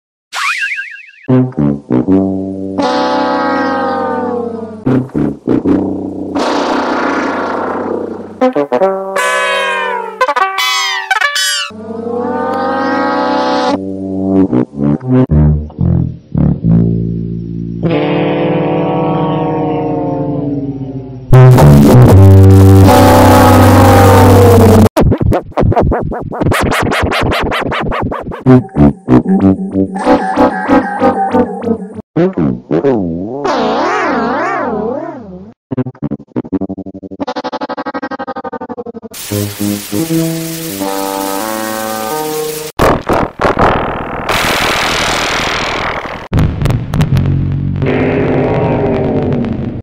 15 Losing Horn Sound Variations sound effects free download